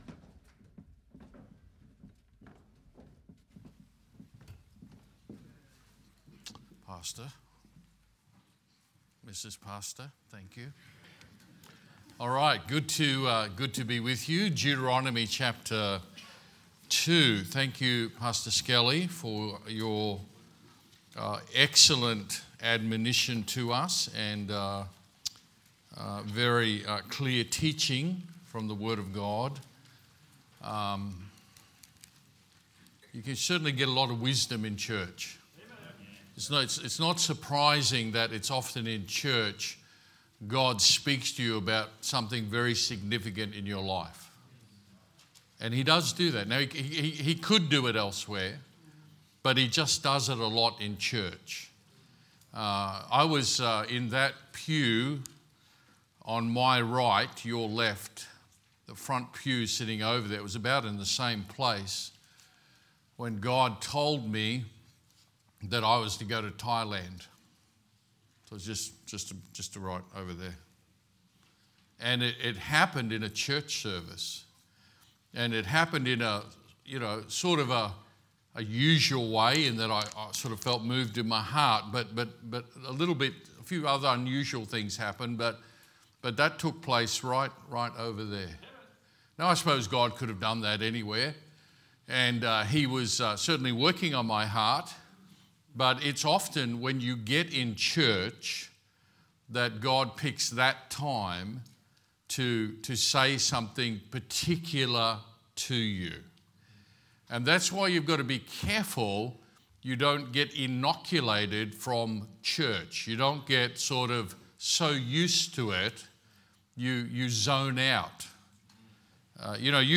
Sermons | Good Shepherd Baptist Church
Leadership Conference 2024